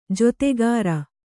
♪ jotegāra